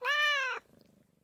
animalia_cat_idle.ogg